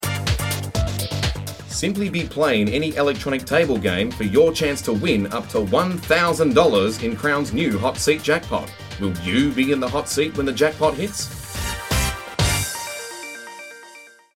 Male
Corporate
Words that describe my voice are Deep, Strong, Projecting.